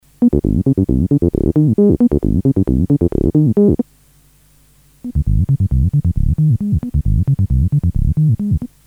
Min cutoff and env mod
mp3 file demonstrates the minimum filter cutoff possible before and after modification.
The pattern plays first on a stock 303, then on a modded unit.
Note that the modded 303 also has a minimum ENV MOD setting of zero - there is always a little modulation on the stock unit.